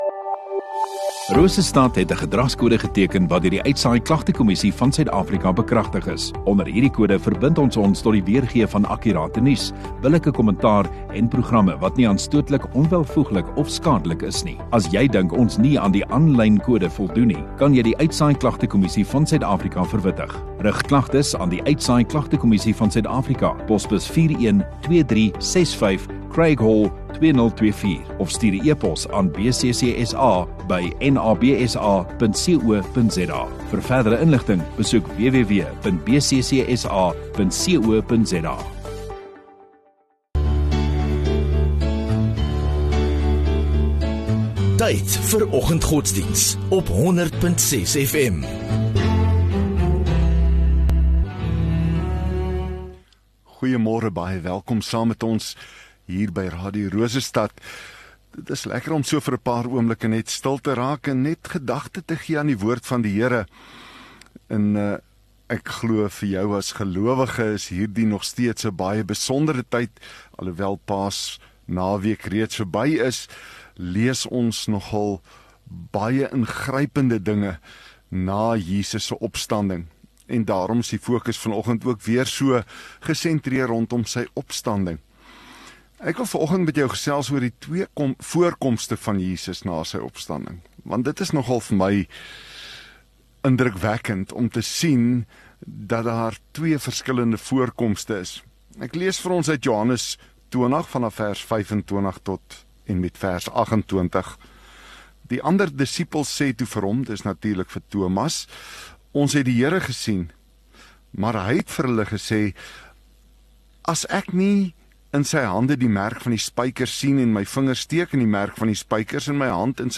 MultiMedia LIVE View Promo Continue Install Rosestad Godsdiens 24 Apr Donderdag Oggenddiens